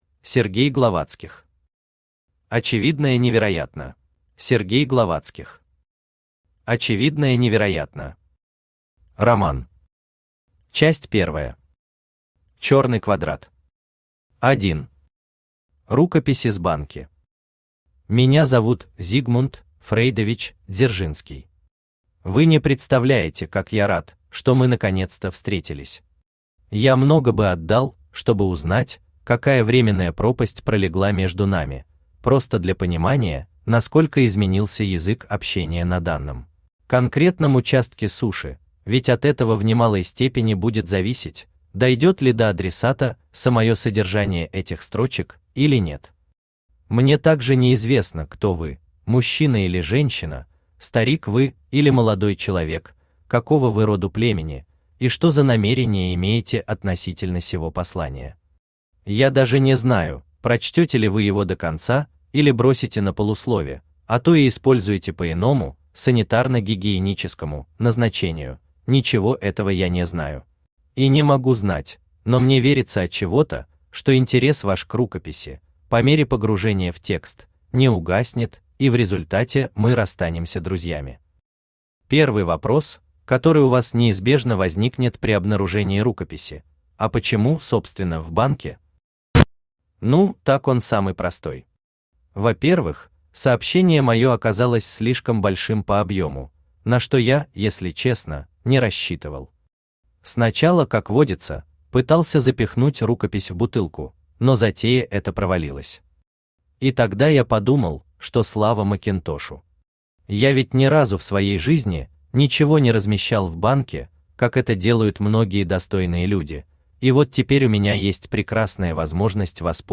Аудиокнига Очевидное-Невероятное | Библиотека аудиокниг
Aудиокнига Очевидное-Невероятное Автор Сергей Владимирович Главатских Читает аудиокнигу Авточтец ЛитРес.